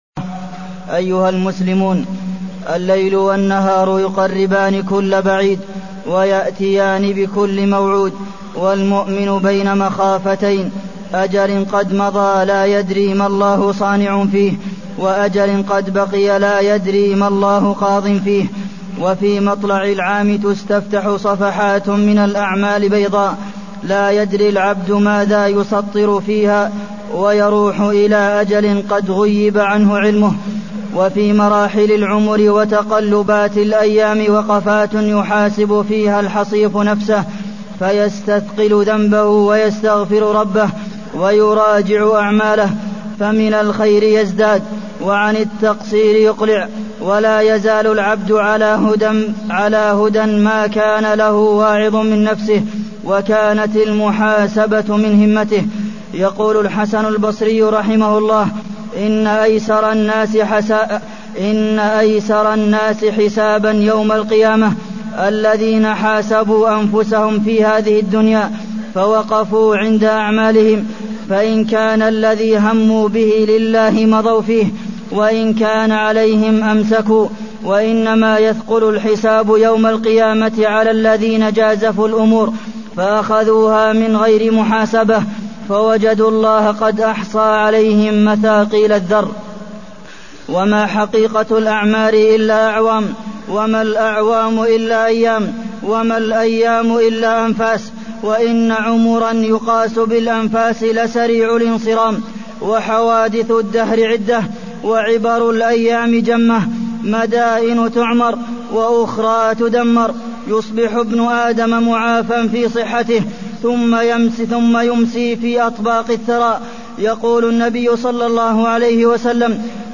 تاريخ النشر ٢ محرم ١٤٢١ هـ المكان: المسجد النبوي الشيخ: فضيلة الشيخ د. عبدالمحسن بن محمد القاسم فضيلة الشيخ د. عبدالمحسن بن محمد القاسم محاسبة النفس